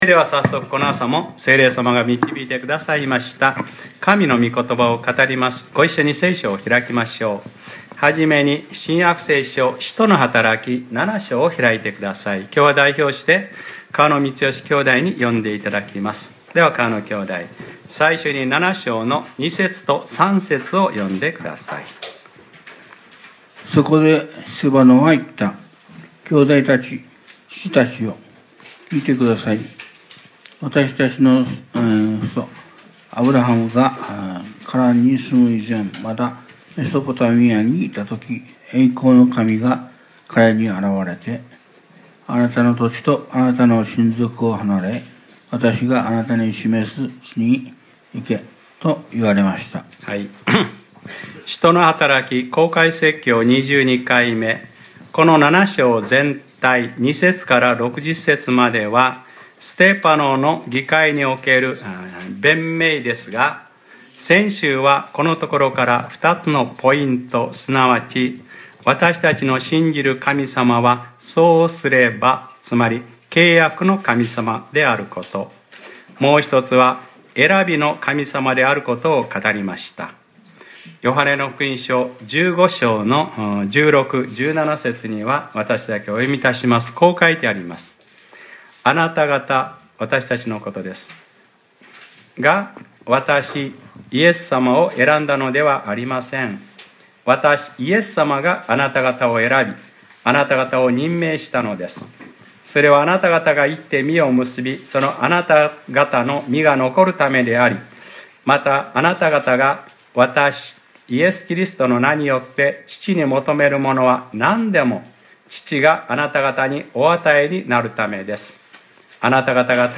2018年05月06日（日）礼拝説教『使徒ｰ22：霊の目を開きなさい』